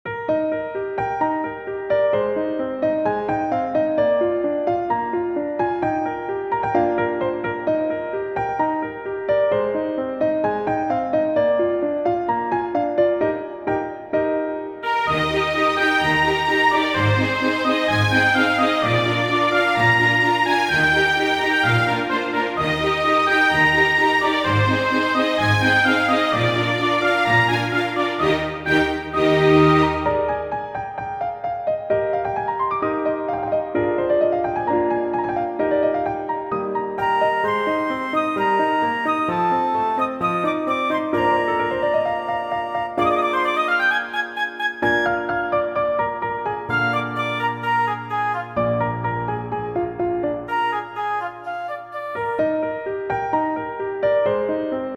ogg(L) 明るい 晴天 ピアノ協奏曲
ウキウキするピアノと爽快なオーケストラ。